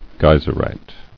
[gey·ser·ite]